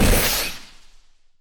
rocket-launch.mp3